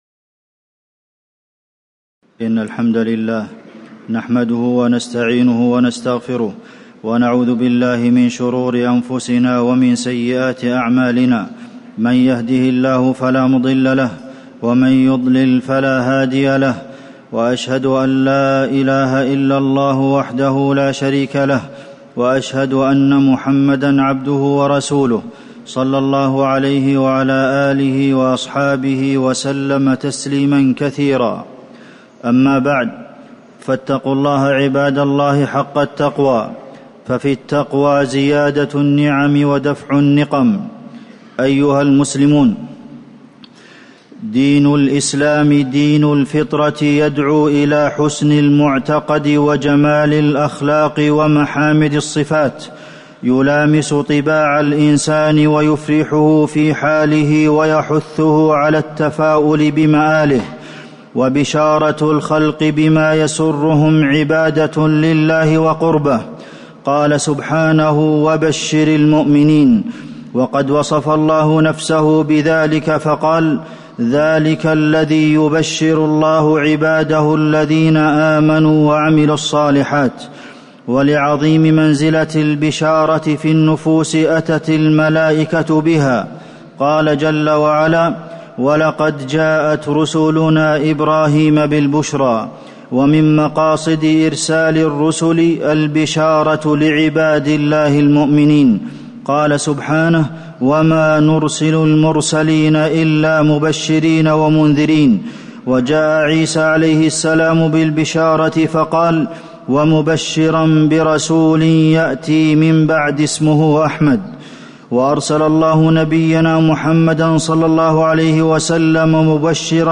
تاريخ النشر ١٩ جمادى الأولى ١٤٤٠ هـ المكان: المسجد النبوي الشيخ: فضيلة الشيخ د. عبدالمحسن بن محمد القاسم فضيلة الشيخ د. عبدالمحسن بن محمد القاسم البشارة